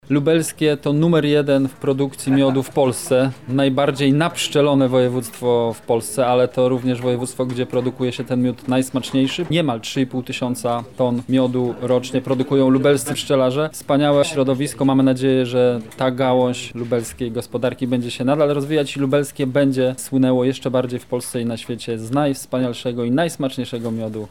-mówi Michał Mulawa, wicemarszałek województwa lubelskiego.